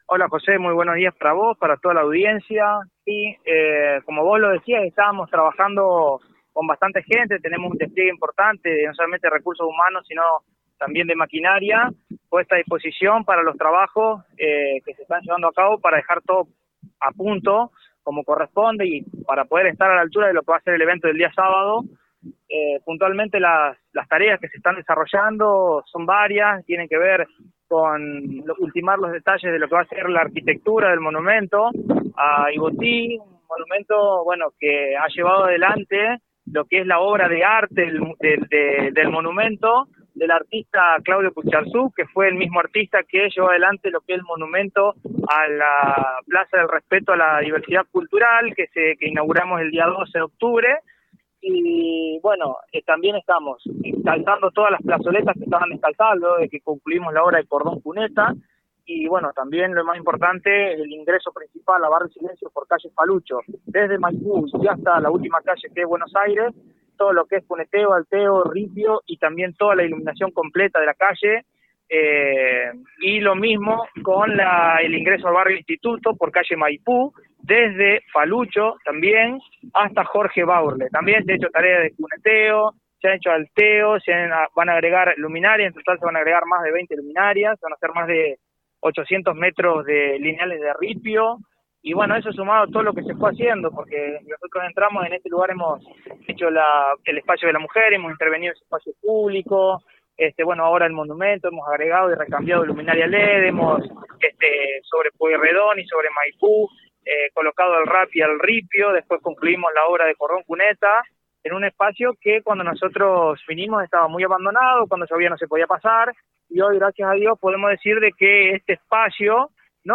Según explicó el secretario de Obras Publicas Efrain Rojas en un móvil que realizó FM CERES 98.7Mhz. En ese sector se construye Ripio e iluminación Led en las calles circundantes.